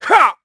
KaselB-Vox_Attack3_kr_b.wav